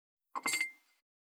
210,机に物を置く,テーブル等に物を置く,食器,グラス,コップ,工具,小物,雑貨,コトン,トン,ゴト,ポン,ガシャン,
コップ効果音物を置く